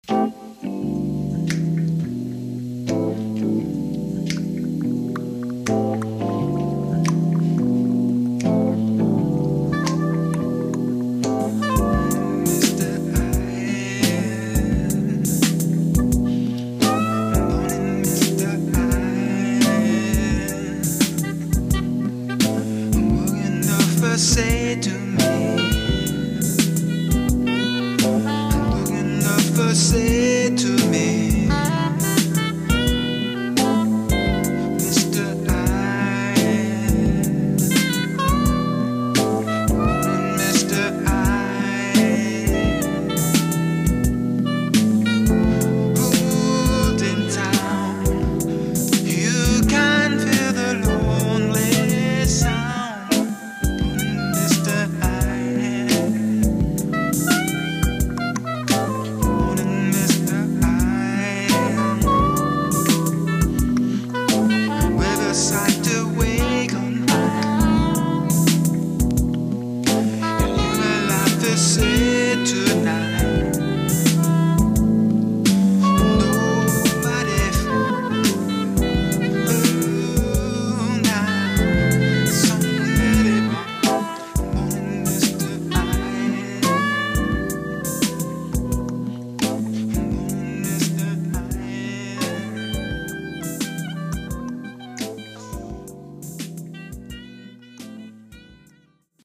Improvisational Music Concept Sketches.
All source material from 4 track analogue tape transferred to WAV (Sonar format). Clips are from the first 4 track analogue console mixes (stereo cassette) on the day the sketch was conceived.
Rough music concepts for vocal song development.
Musical intent is focused on exploring new harmonic and rhythmic relationships thru improvised or spontaneous composition while maintaining some pop music sensibilities. All vocals are improvised using semi-lingual scatspeak to indicate musical intent for lyric writers (pardon the bad singing).